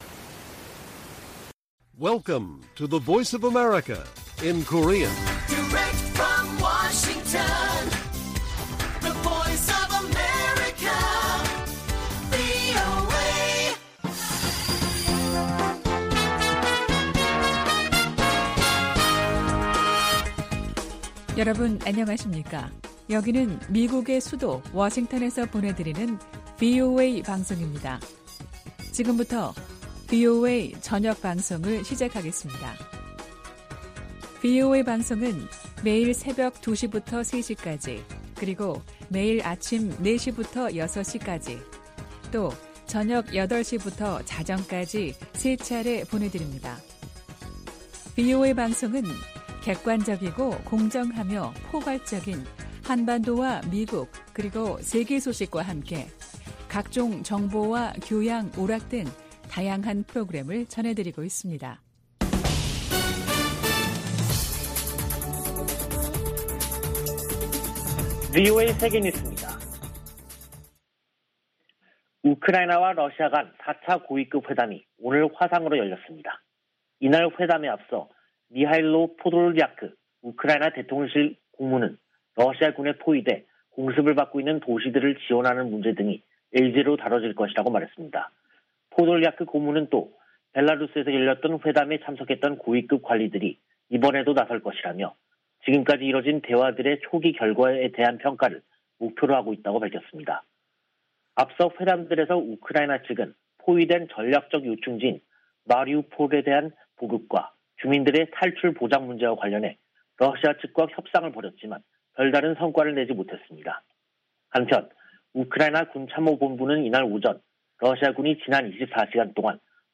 VOA 한국어 간판 뉴스 프로그램 '뉴스 투데이', 2022년 3월 14일 1부 방송입니다. 성 김 미 대북특별대표가 중국에 북한이 도발을 중단하고 대화에 복귀하도록 영향력을 발휘해 달라고 요구했습니다. 미 국무부는 북한 탄도미사일 발사 등이 역내 가장 긴박한 도전이라고 지적하며 한국 차기 정부와의 협력을 기대했습니다.